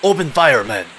Human Male, Age 26